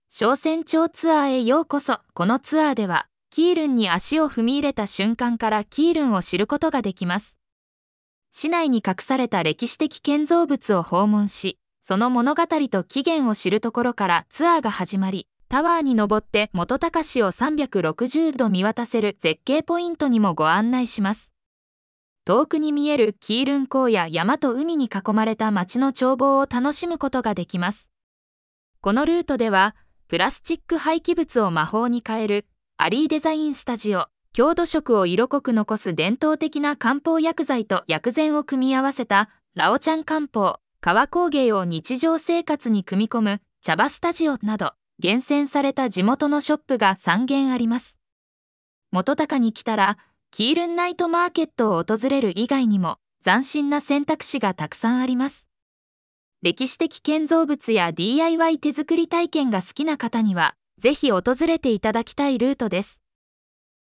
オーディオガイド